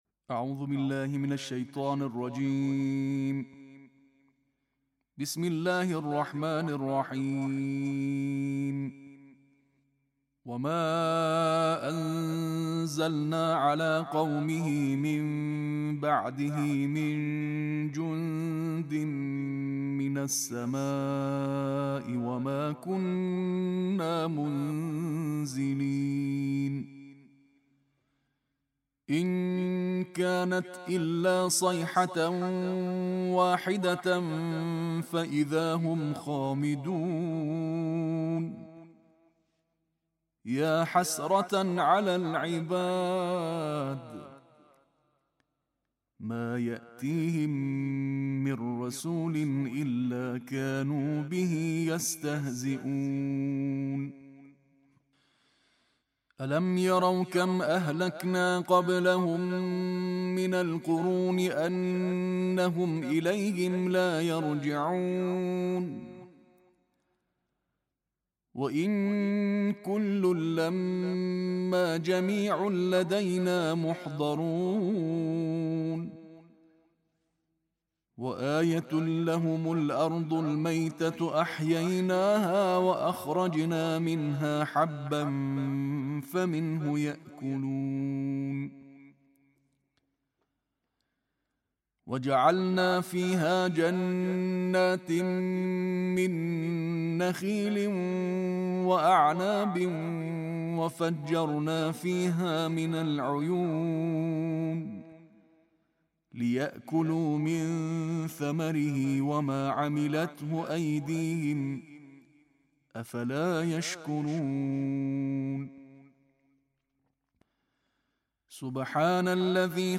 ایکنا: رمضان المبارک کے حوالے سے تئیسویں پارے کی تلاوت ایرانی انٹرنیشنل قاری کی آواز میں ایکنا نے وائرل کی ہے.